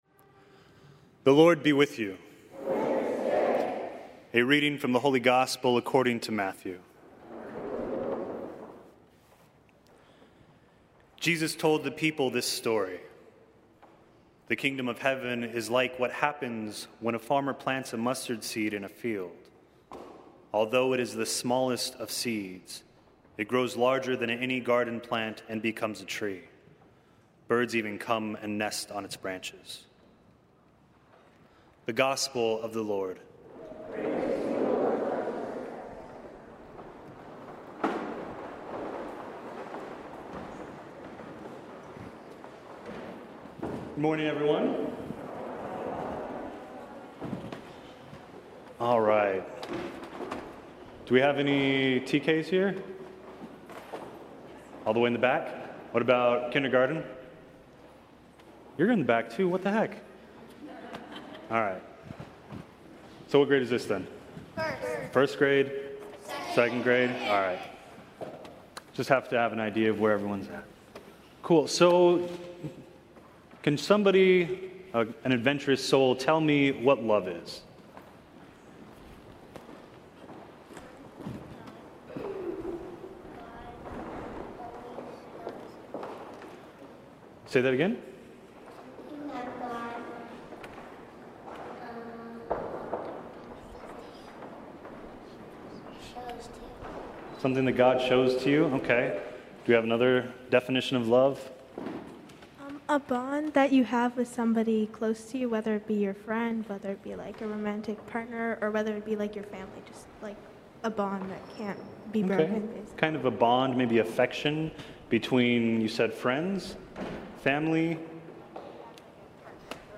Holy Family School, Last Mass of the Year 2022